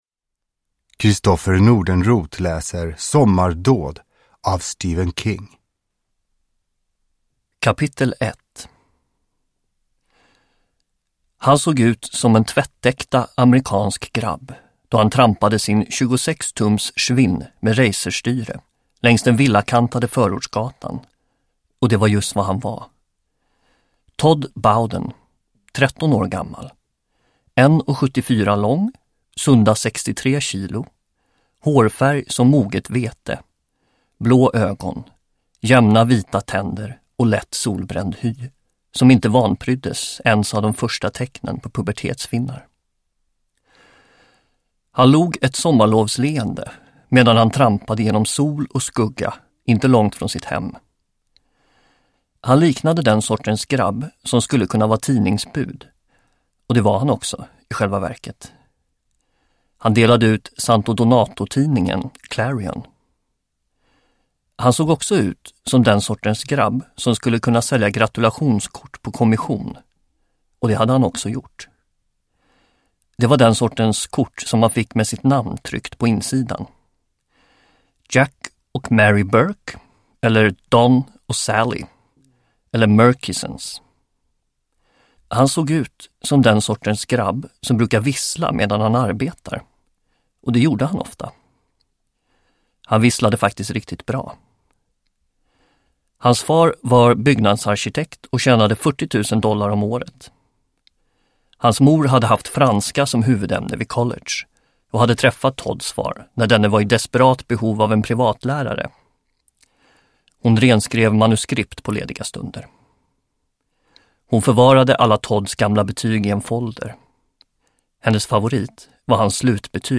Sommardåd. En av berättelserna ur novellsamlingen Årstider – Ljudbok